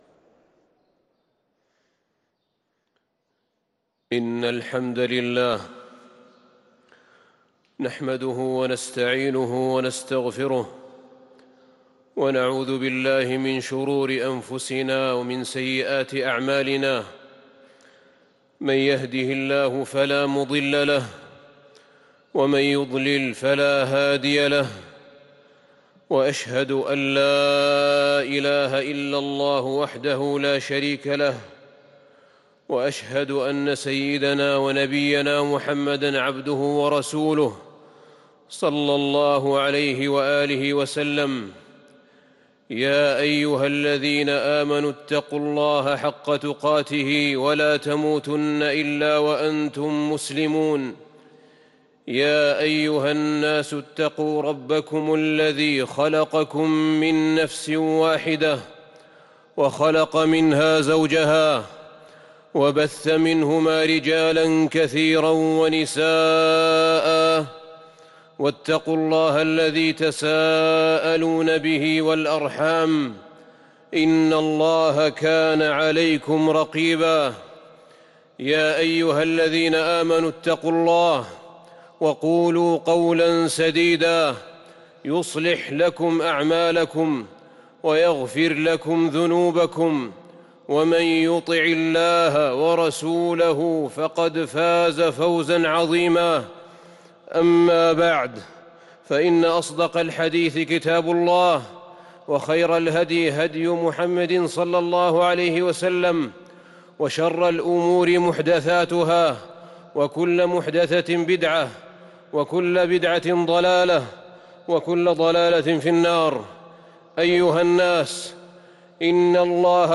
خطبة الجمعة 3-7-1443هـ | khutbat aljumuea 4-2-2022 > خطب الحرم النبوي عام 1443 🕌 > خطب الحرم النبوي 🕌 > المزيد - تلاوات الحرمين